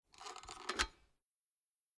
Toaster ELIC | Die Neue Sammlung
11008_Aufklappen.mp3